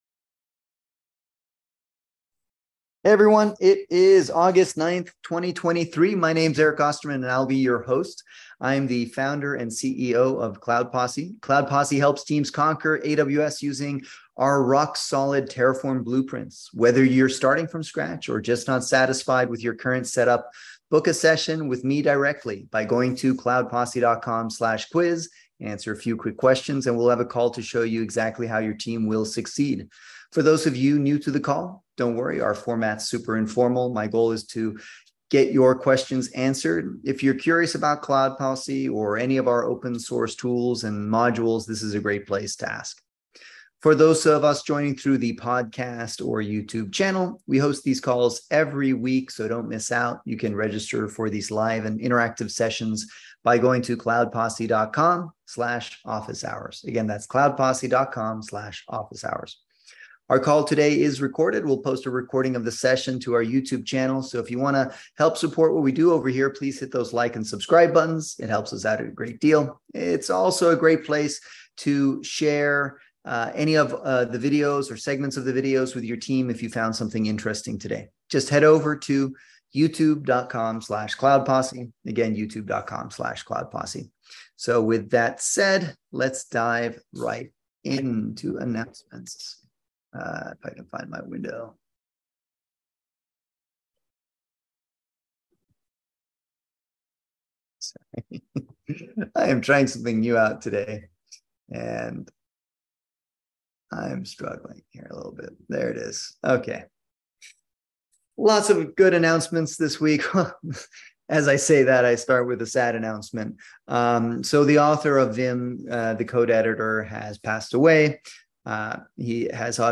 Cloud Posse holds public "Office Hours" every Wednesday at 11:30am PST to answer questions on all things related to DevOps, Terraform, Kubernetes, CICD. Basically, it's like an interactive "Lunch & Learn" session where we get together for about an hour and talk shop.